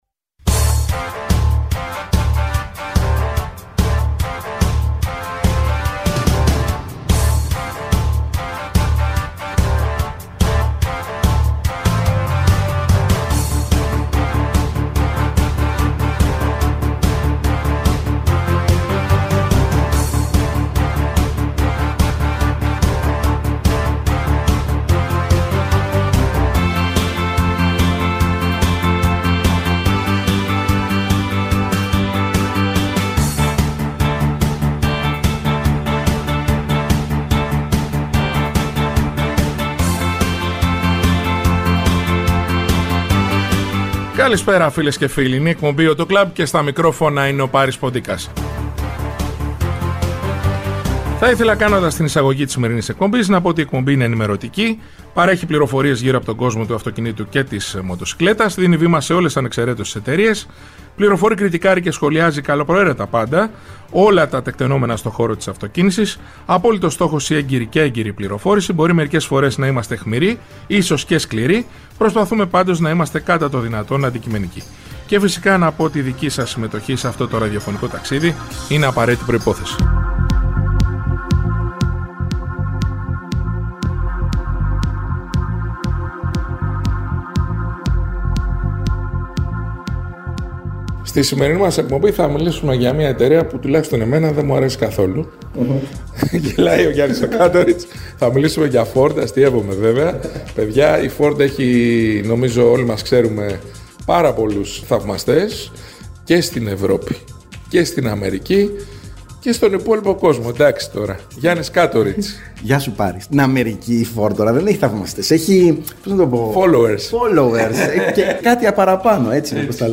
Η εκπομπή «AUTO CLUB» είναι ενημερωτική, παρέχει πληροφορίες γύρω από τον κόσμο του αυτοκινήτου και της μοτοσικλέτας, δίνει βήμα σε όλες ανεξαιρέτως τις εταιρείες, φιλοξενεί στο στούντιο ή τηλεφωνικά στελέχη της αγοράς, δημοσιογράφους αλλά και ανθρώπους του χώρου.